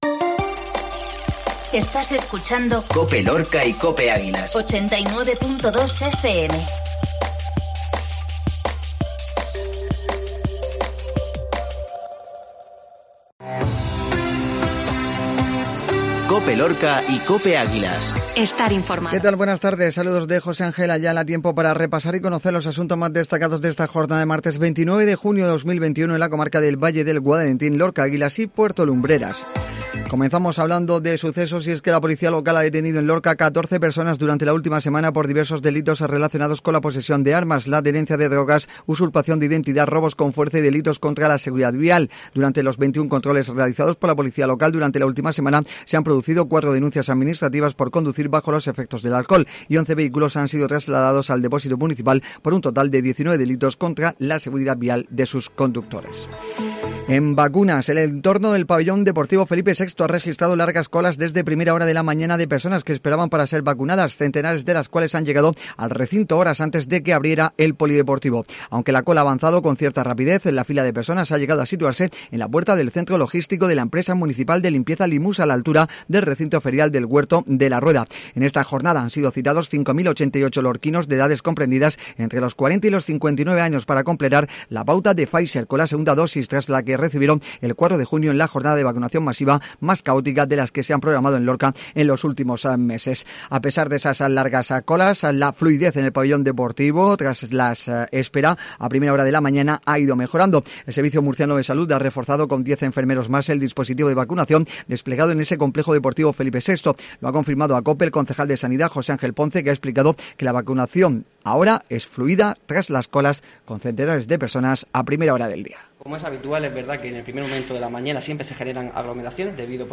INFORMATIVO MEDIODÍA MARTES